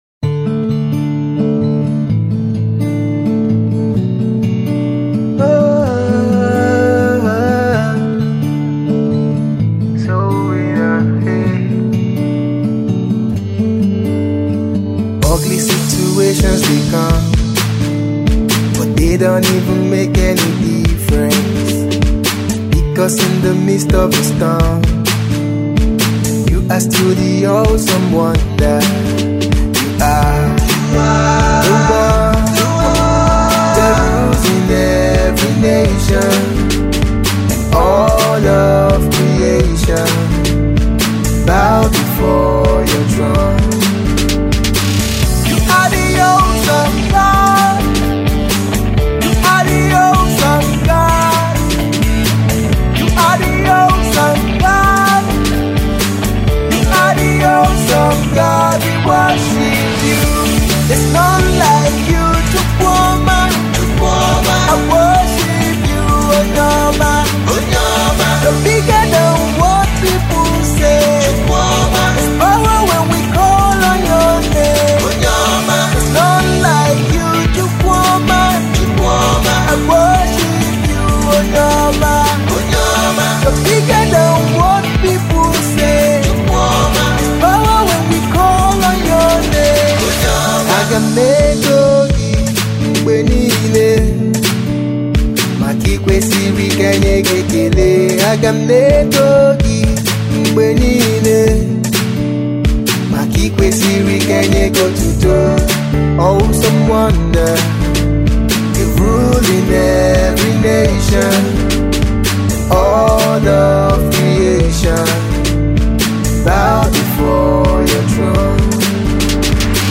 Urban Gospel Music Minister